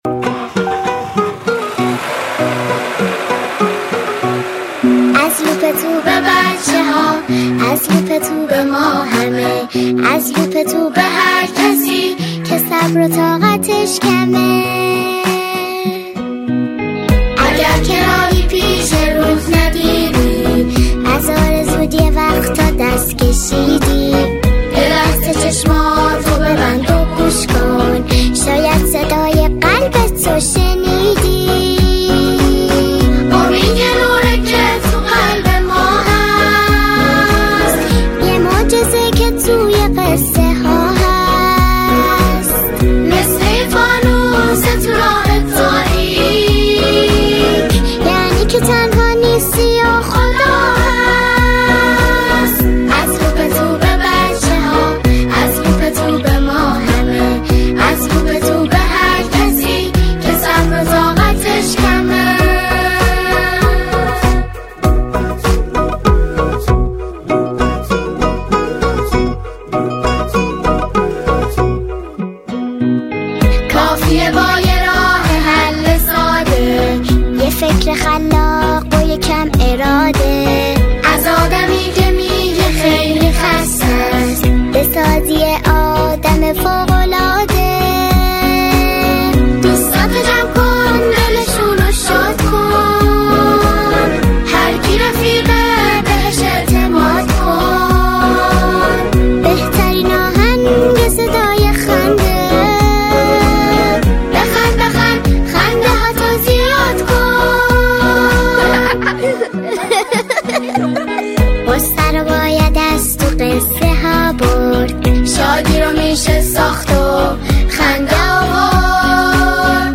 سرود کودکانه